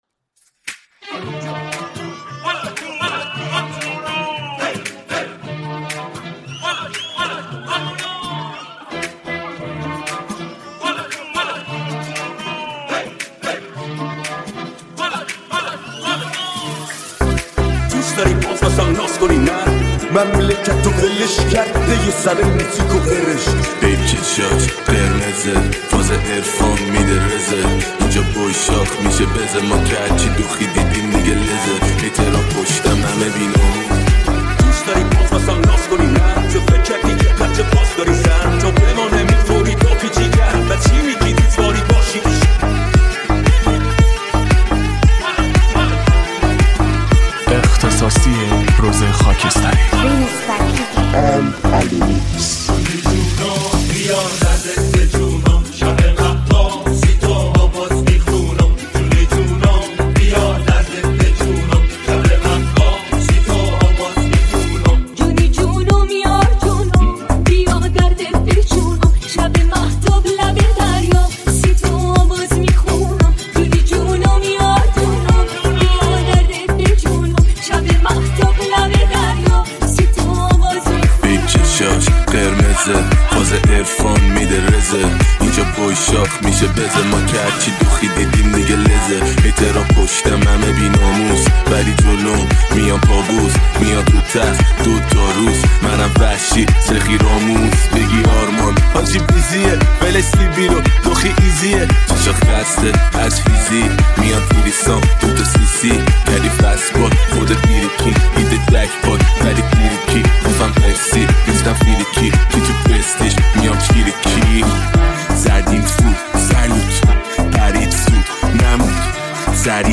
ریمیکس شاد
ریمیکس تریبال
ریمیکس رپ